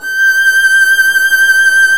Index of /90_sSampleCDs/Roland - String Master Series/STR_Violin 1-3vb/STR_Vln1 % marc